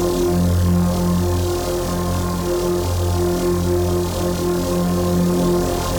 Index of /musicradar/dystopian-drone-samples/Non Tempo Loops
DD_LoopDrone1-F.wav